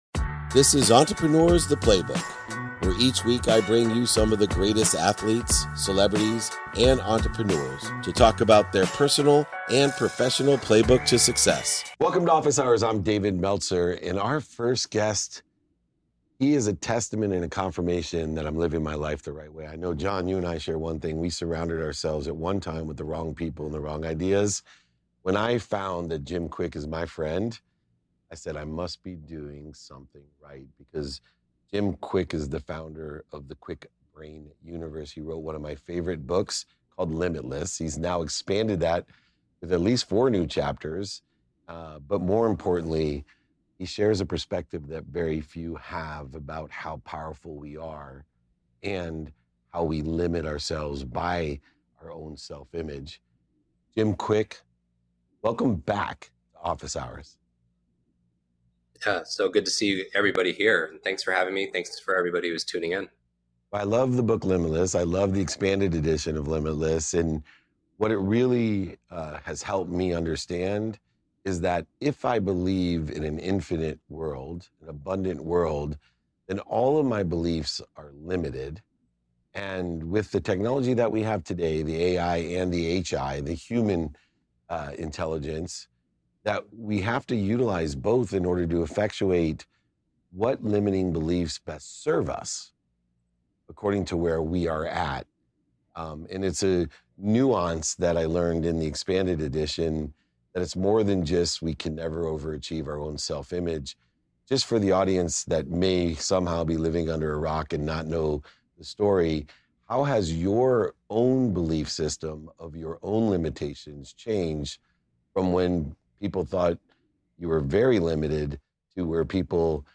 In today's episode, I'm joined by Jim Kwik, the renowned brain coach, author of the bestselling book "Limitless," and the mind behind the Kwik Learning platform. Jim shares insights on how adopting an abundant mindset can dramatically shift our self-imposed limits.